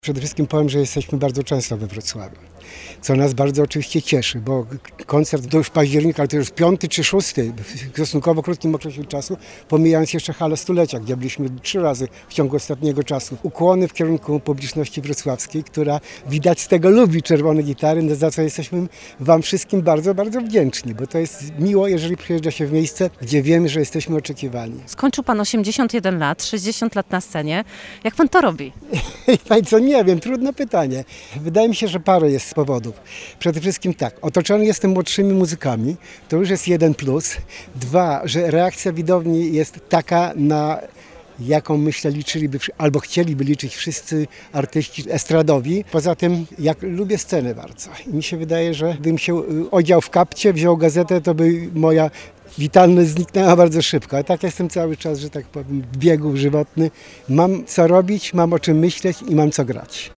Jeden z założycieli grupy, perkusista Jerzy Skrzypczyk nie krył radości z koncertowania i spotkań z ukochaną publicznością.